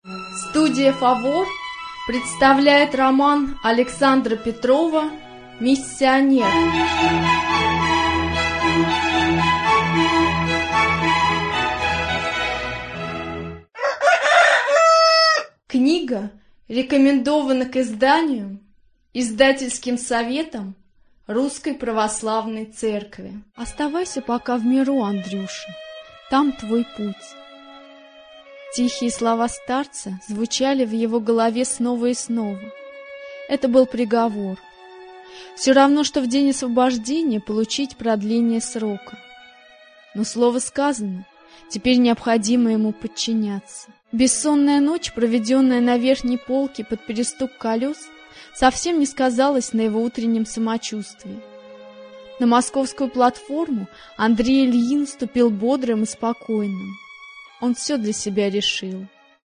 Аудиокнига Миссионер | Библиотека аудиокниг